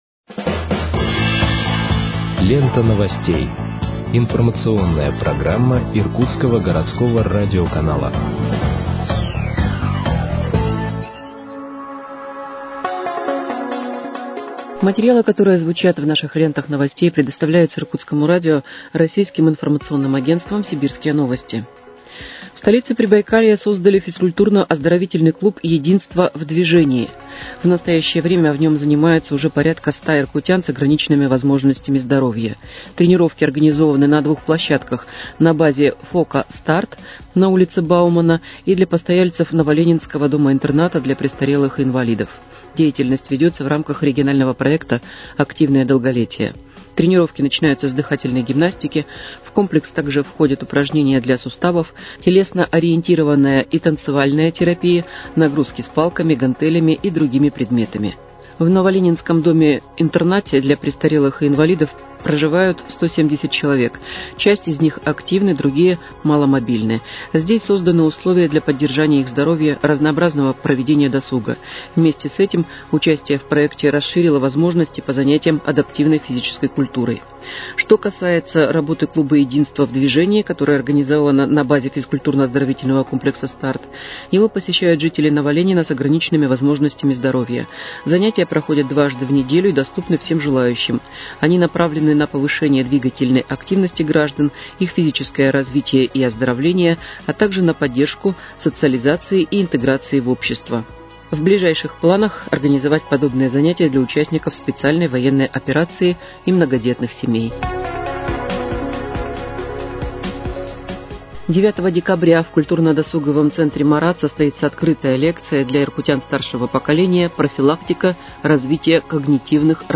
Выпуск новостей в подкастах газеты «Иркутск» от 8.12.2025 № 1